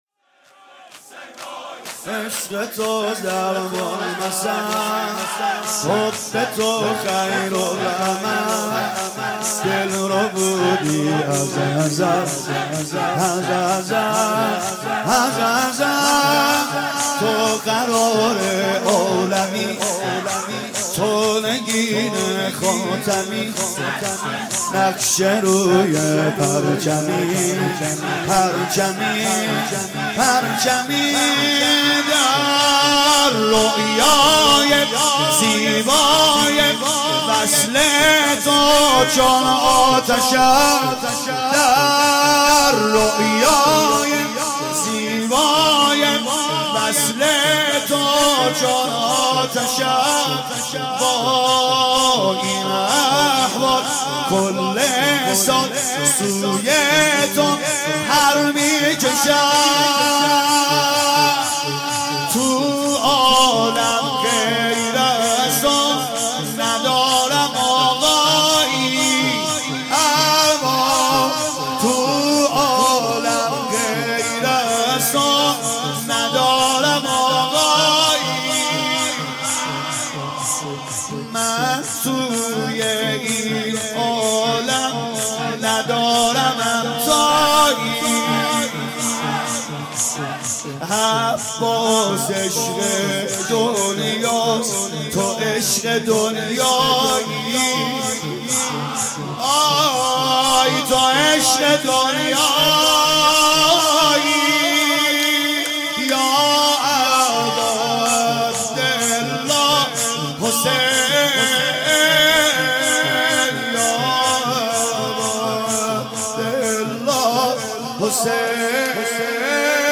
خیمه گاه - هیئت دانشجویی فاطمیون دانشگاه یزد - شور|سید رضا نریمانی|عشق تو ضرب المثل|فاطمیه اول ۹۴